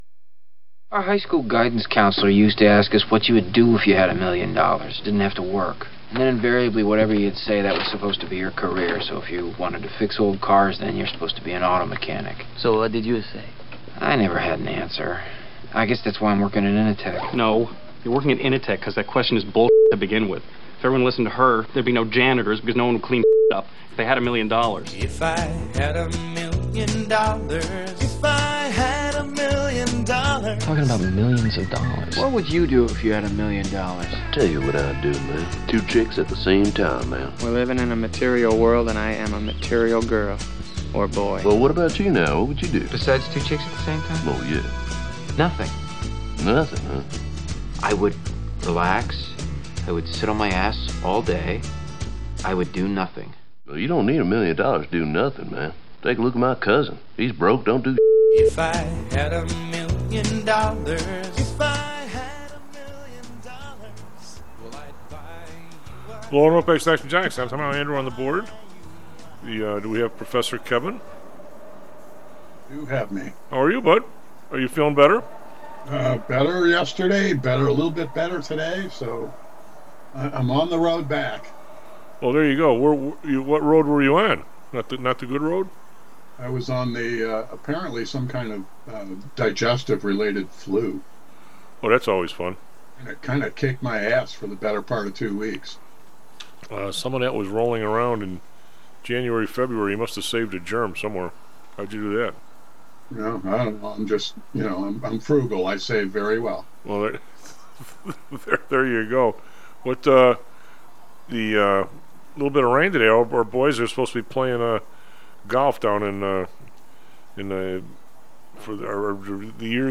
calls in to discuss the odd behavior of the market today.